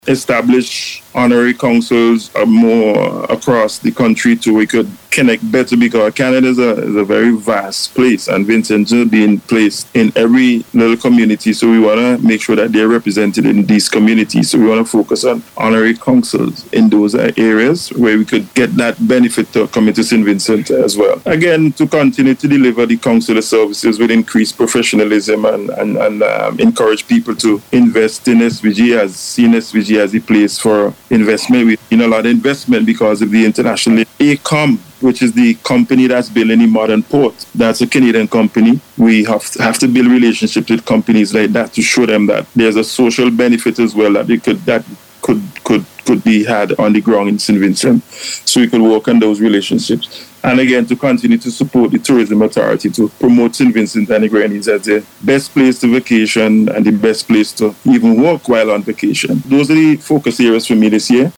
Huggins, who was speaking on NBC Radio earlier this week said he is looking for opportunities in the areas of maritime, technical and vocational education and agriculture to benefit the people of St. Vincent and the Grenadines.